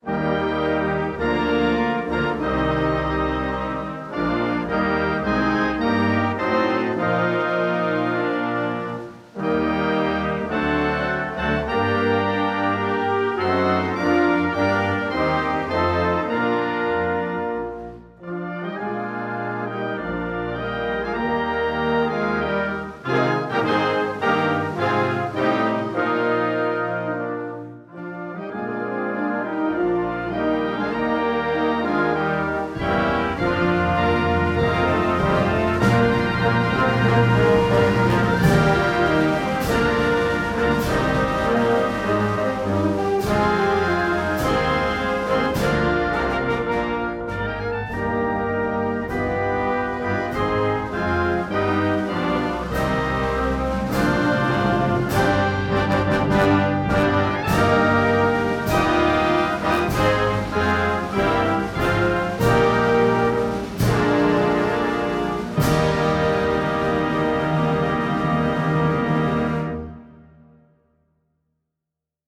Version de « Ô Canada » de la musique militaire
Hymne national du Canada
O Canada, l’hymne national du Canada, version de musique militaire en mi ♭ majeur.